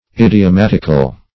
Idiomatic \Id`i*o*mat"ic\, Idiomatical \Id`i*o*mat"ic*al\, a.